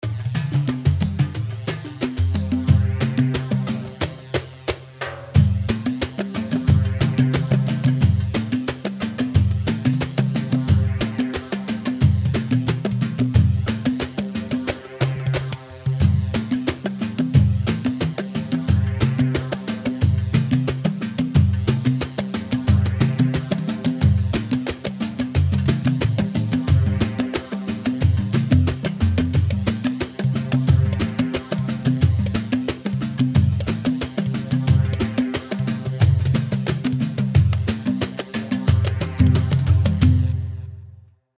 Yemeni
Bdwi Thefa 4/4 180 بدوي تحيفة
Badawi-Taheefa-4-4-180.mp3